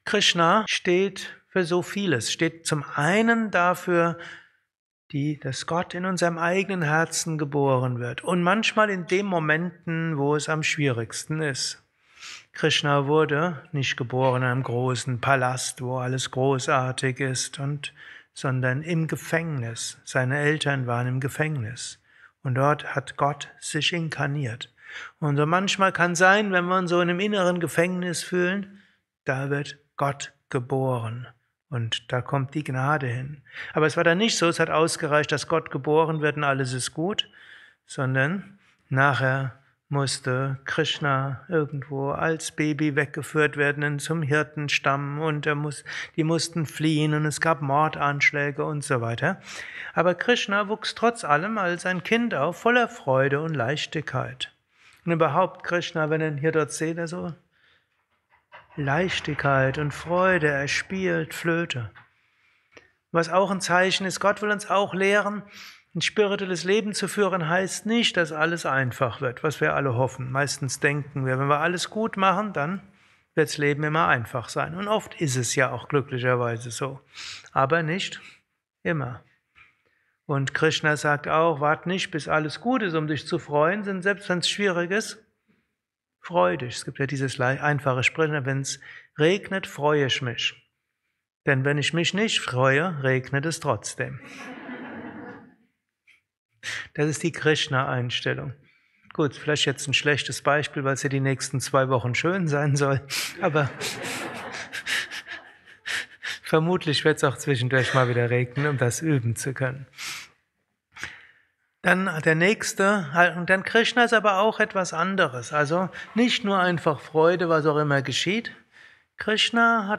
kurzer Vortrag als Inspiration für den heutigen Tag von und mit
eines Satsangs gehalten nach einer Meditation im Yoga Vidya